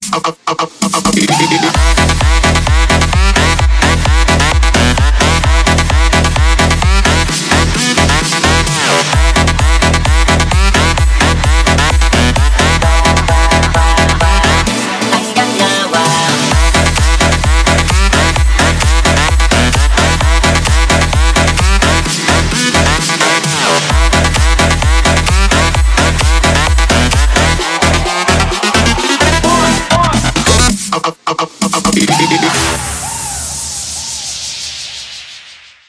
club
Big Room
progressive house
electro house